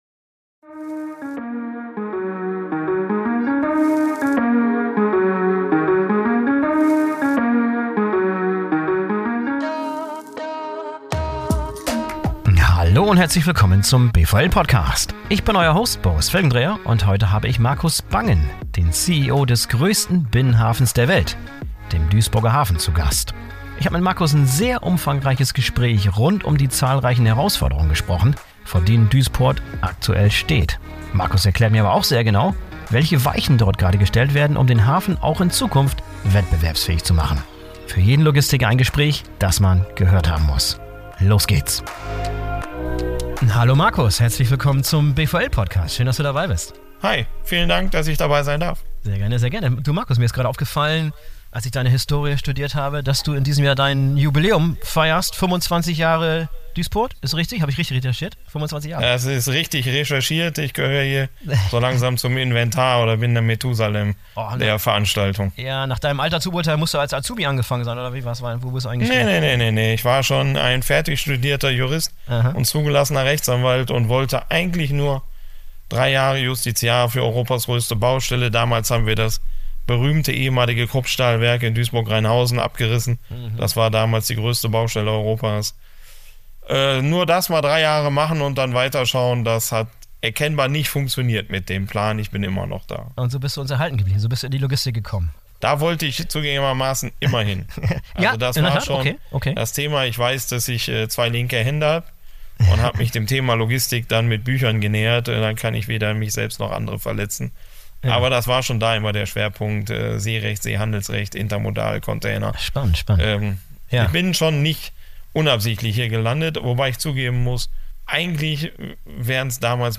In einem ausführlichen, persönlichen Gespräch, das einen echten Blick hinter die Kulissen der Unternehmen, der Personen und deren Ideen und Herausforderungen ermöglicht.